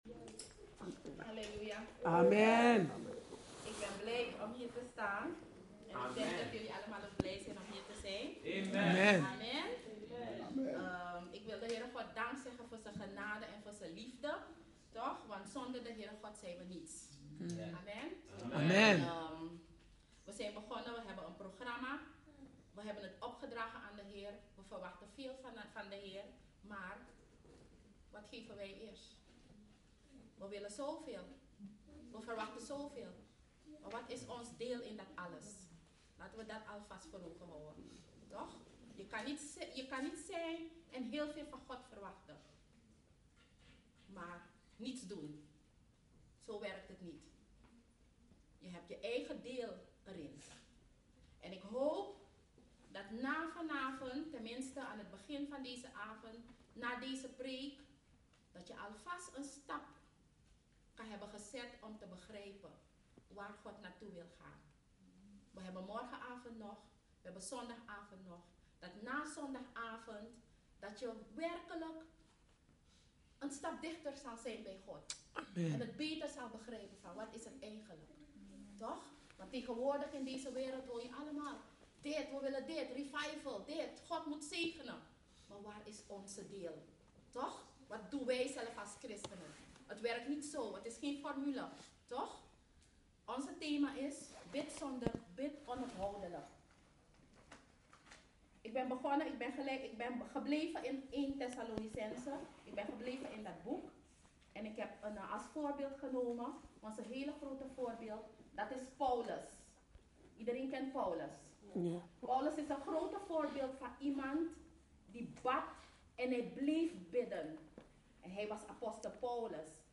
Dutch Assembly